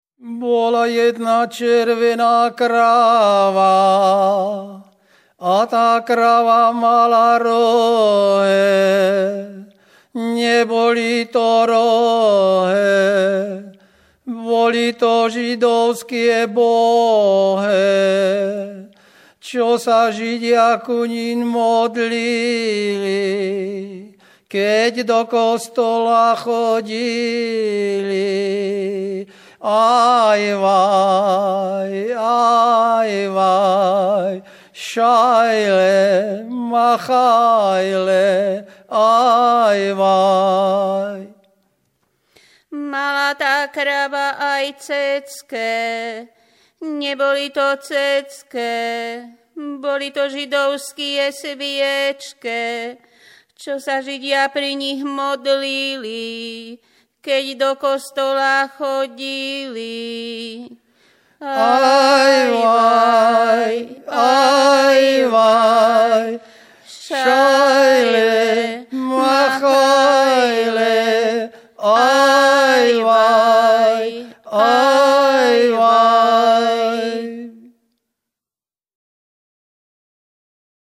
Popis sólo mužský spev bez hudobného sprievodu
Miesto záznamu Ostrá Lúka
Predmetová klasifikácia 11.2. Piesne späté s kalendárnym obyčajovým cyklom